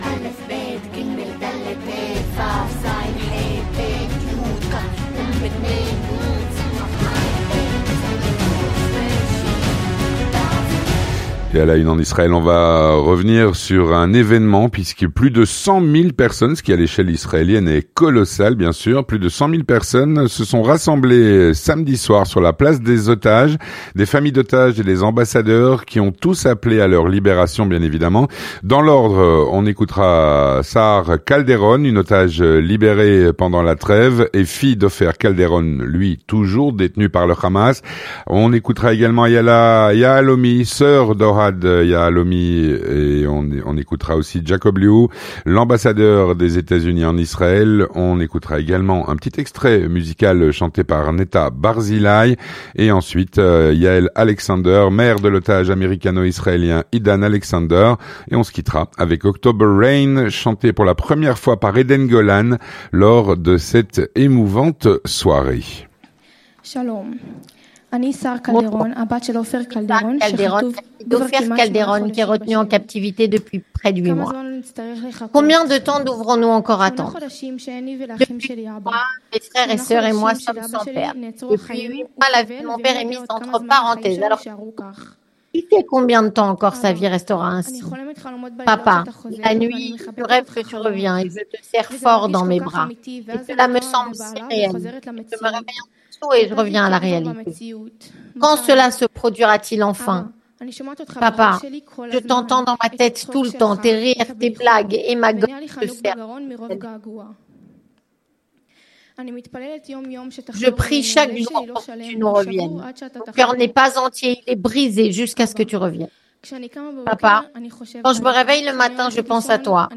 Plus de 100 000 personnes se sont rassemblées samedi soir sur la place des otages. Des familles d’otages et des ambassadeurs qui ont tous appelé à leur libération.
Jacob Lew, ambassadeur des États-Unis en Israël. Un petit extrait chanté par Netta Barzilai.
Un reportage